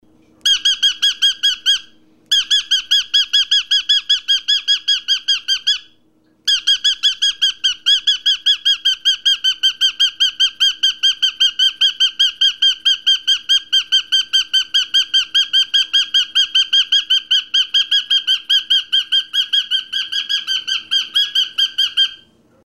На этой странице собраны звуки, которые издают попугаи: от веселого чириканья до мелодичного пения.
Звук курлыканья и свист попугая